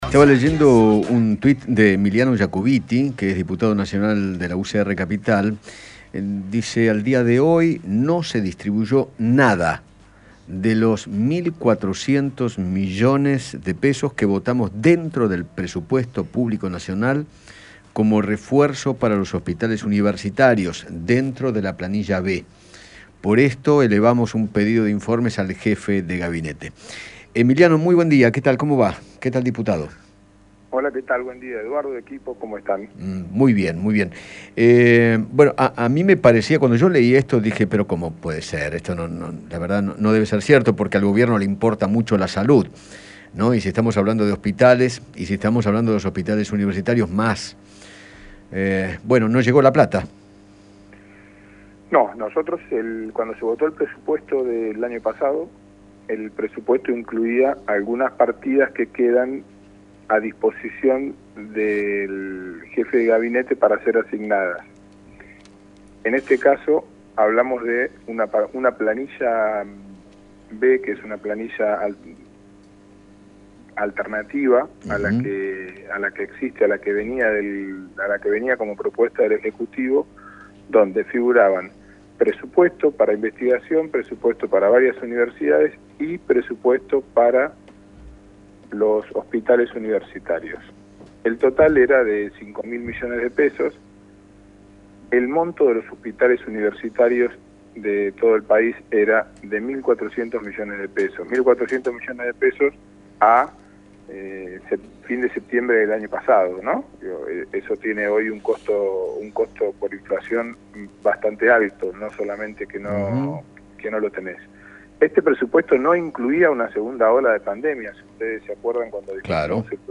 Emiliano Yacobitti, diputado nacional, dialogó con Eduardo Feinmann sobre el reclamo que le realizará al jefe de Gabinete por el incumplimiento del Presupuesto Público Nacional votado el año pasado.